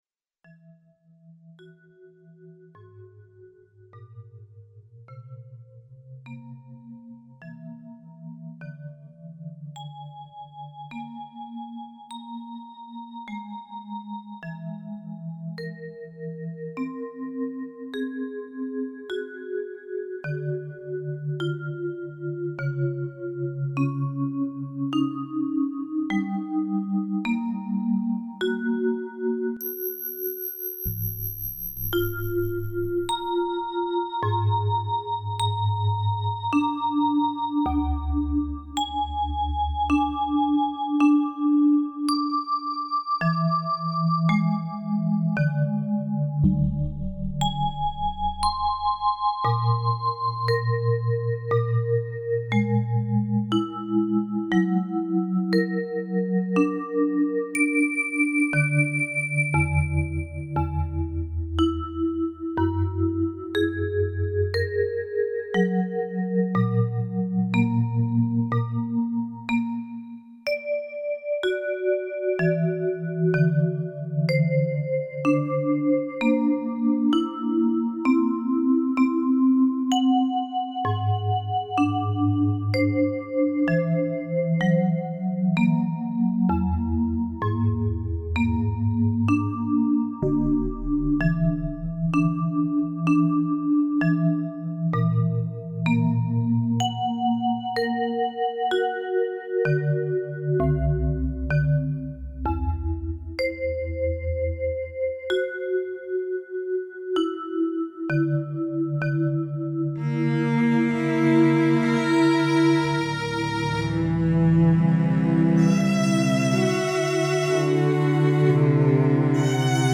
GCP Vibe&Cell "This a GCP-to-MIDI track (in mp3 format) recorded using a Vibraphone instrument instead of the standard piano default (for approx the first 2 minutes) and then, I switch the instrument to a Cello for the rest of the track.
GCP2Midi.Vib.Cello.mp3